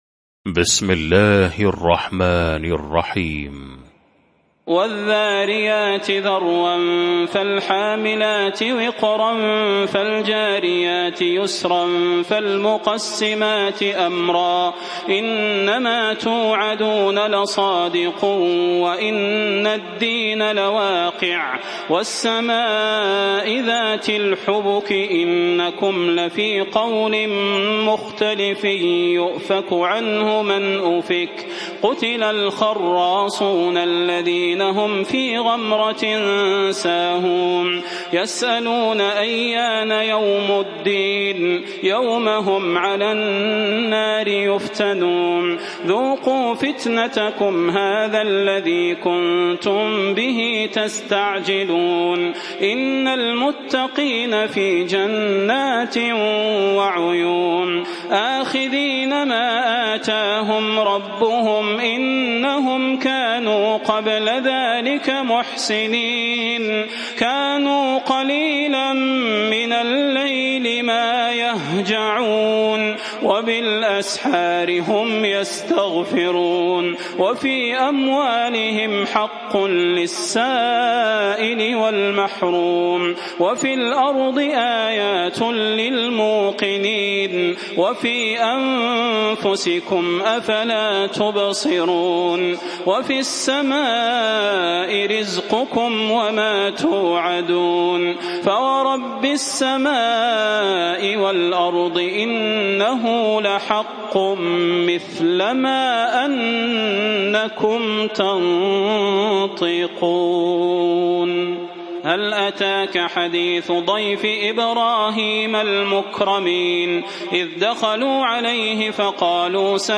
المكان: المسجد النبوي الشيخ: فضيلة الشيخ د. صلاح بن محمد البدير فضيلة الشيخ د. صلاح بن محمد البدير الذاريات